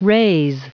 Prononciation du mot raze en anglais (fichier audio)
Prononciation du mot : raze